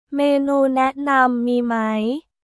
メーヌー ナンナム ミー マイ？